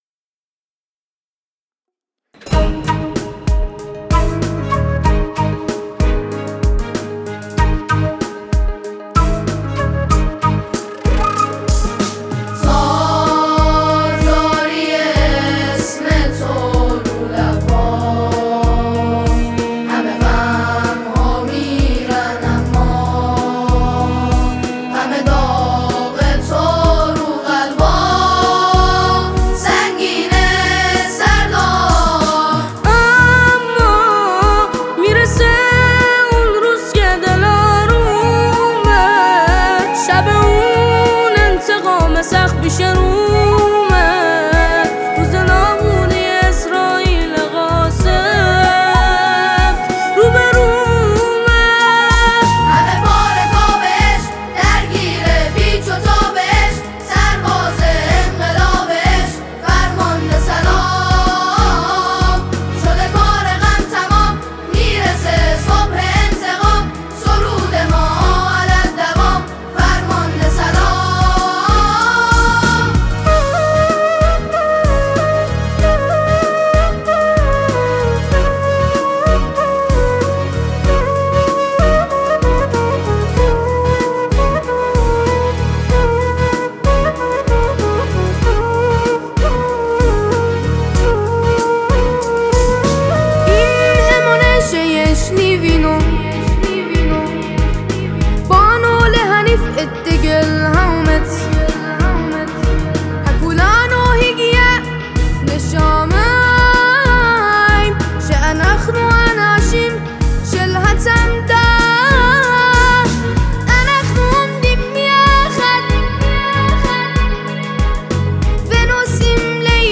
اجرای گروه سرود ری نوا